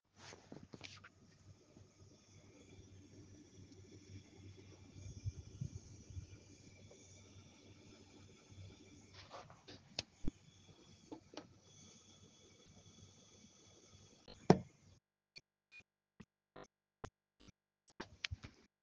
Komplett Neue hardware 2 Tage Alt & Plötzlich Spulenfiepen
Ich habe vor 2 Tagen mein neues Setup gebaut & seit 2-3 Stunden alles "über" 60 FPS Fiepst wie bescheuert.